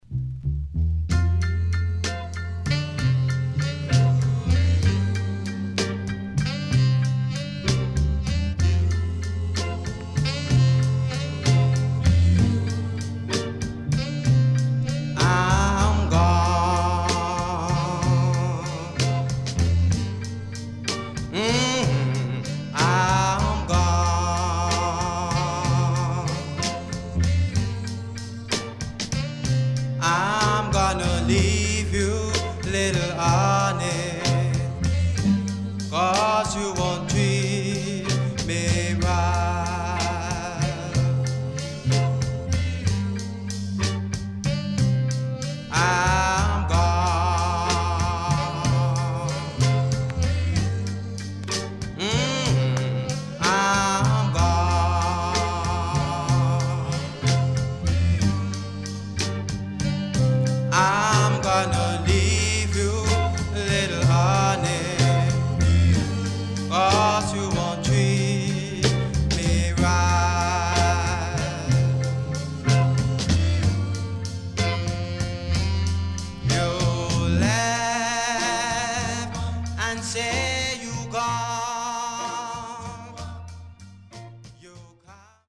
Nice Ska Vocal.Good Condition
SIDE A:少しノイズ入りますが良好です。